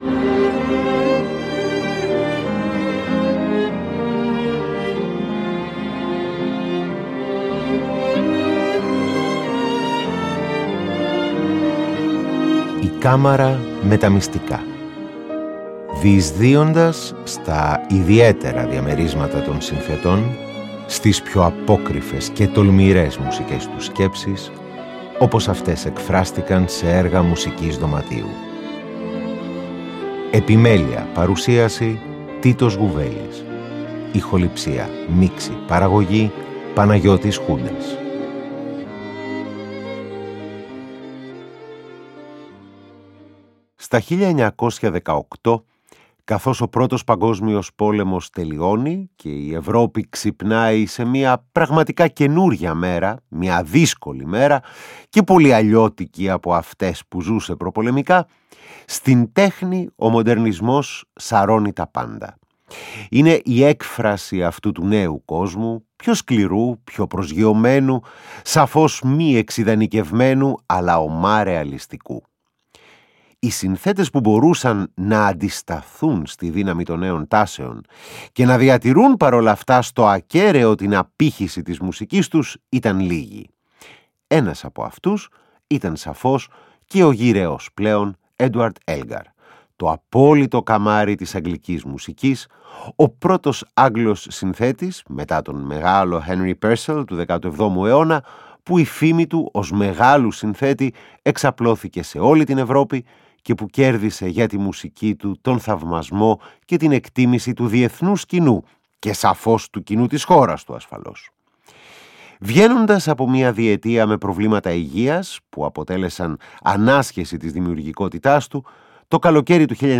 Η περιήγησή μας στη μουσική δωματίου της Μ. Βρετανίας συνεχίζεται και ακούμε τρία σημαντικά ρομαντικά έργα με επίκεντρο τα έγχορδα και guest star είτε το πιάνο, είτε ξύλινα πνευστά. Σε αντιδιαστολή, κάνοντας ένα άλμα στον χρόνο προς τα μπρος ακούμε μουσική για κουαρτέτο εγχόρδων του σπουδαίου πρωτοπόρου Harrison Birtwistle!